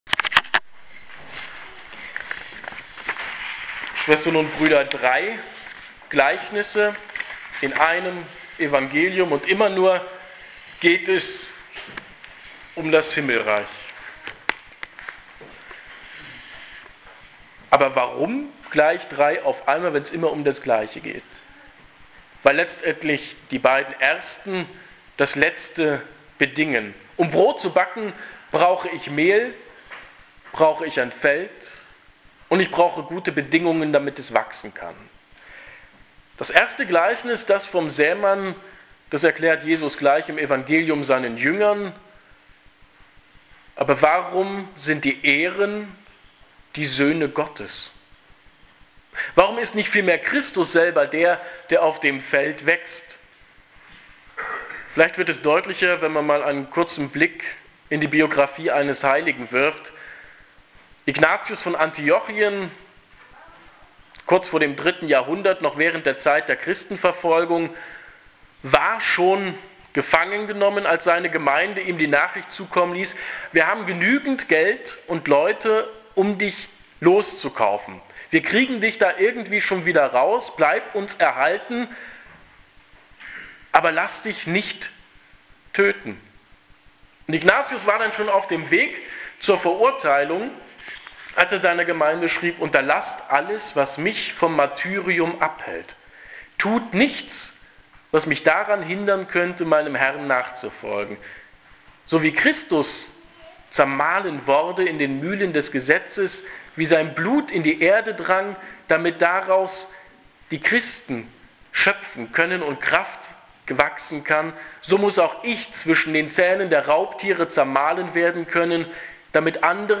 Predigten im Jareskreis
Das Himmelreich im Brot – Predigt vom 16. So. i. Jkr. Lj. A